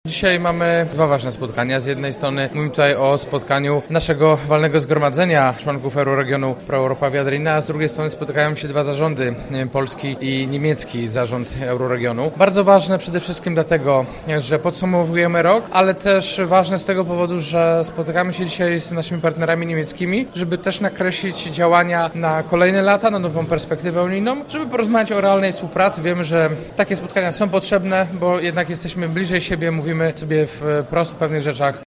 – To ważne, by takie spotkania się odbywały – mówi Jacek Wójcicki, prezydent miasta: